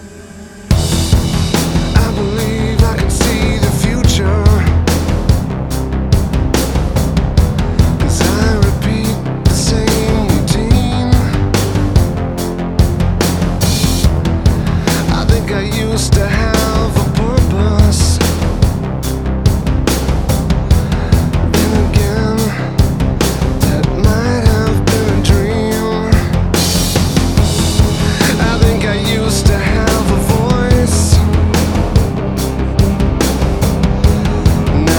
Жанр: Рок / Альтернатива / Электроника / Метал